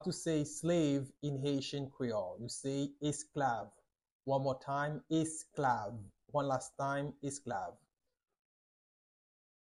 Pronunciation:
28.How-to-say-Slave-in-Haitian-Creole-–-Esklav-with-pronunciation.mp3